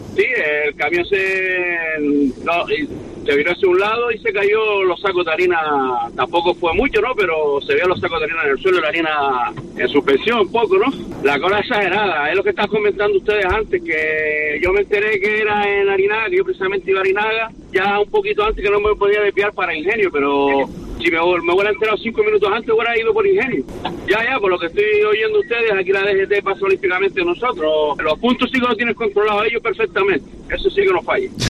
Oyente se queja de las retenciones